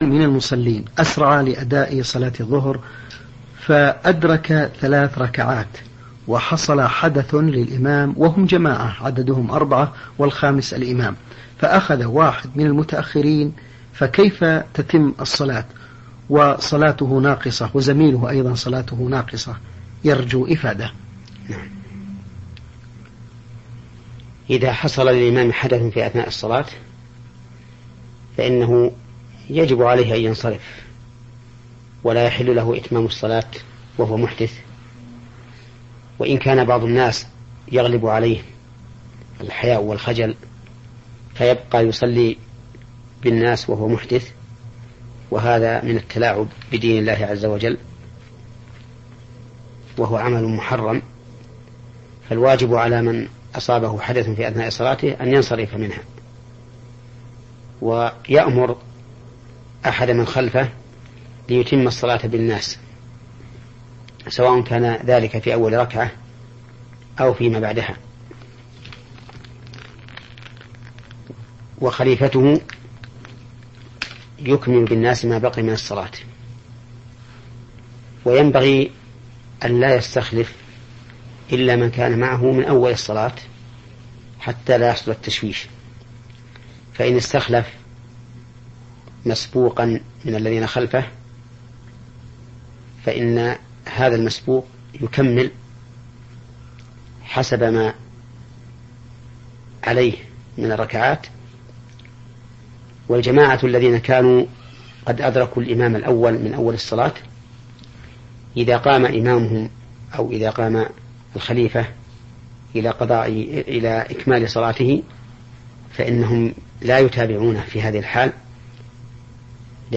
💥 فضيلة الشيـــــخ العلامة الفقيه : محمد بن صالح العثيمين رحمه الله